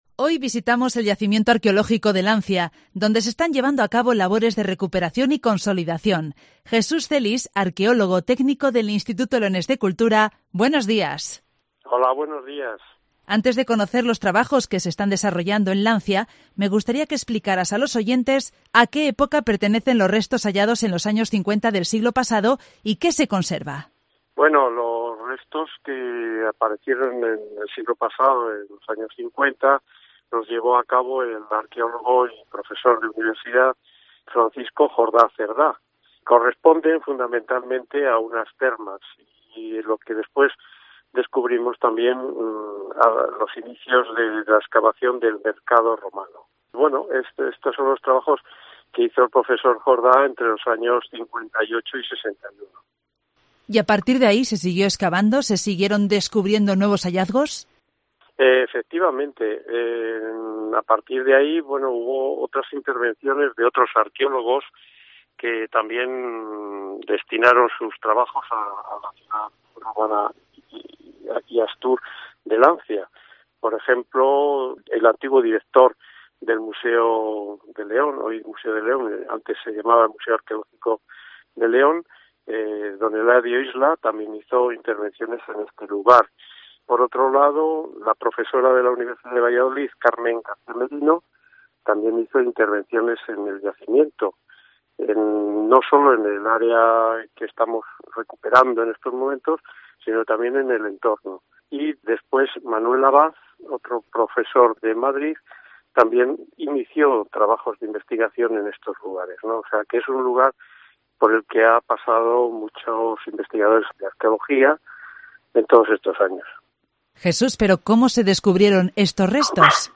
Hoy en Cope León visitamos el yacimiento arqueológico de Lancia